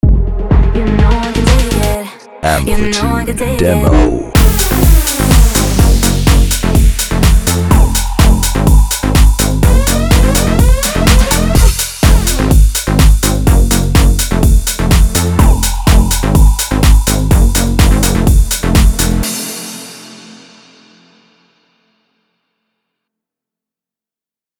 125 BPM  F Minor  4A
Bass House